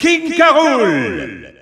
Announcer pronouncing King K. Rool in French PAL.
King_K._Rool_French_EU_Announcer_SSBU.wav